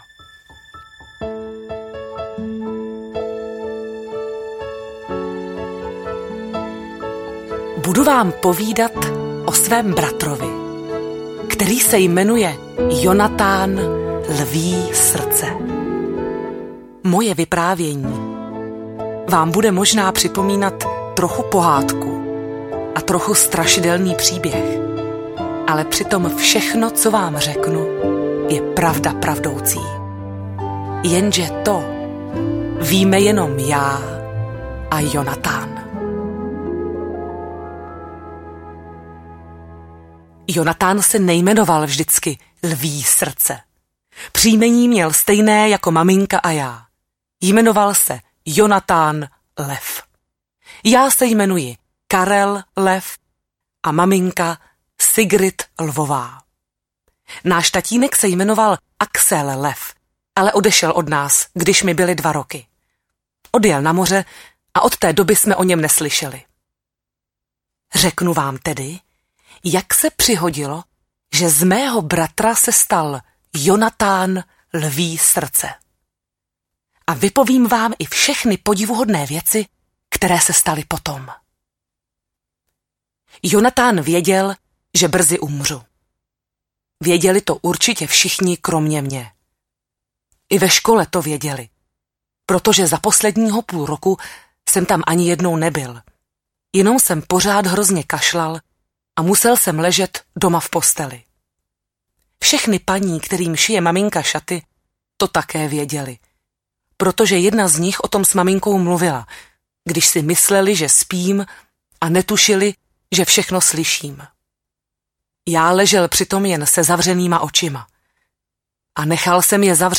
Bratři Lví srdce audiokniha
Ukázka z knihy
• InterpretTereza Kostková
bratri-lvi-srdce-audiokniha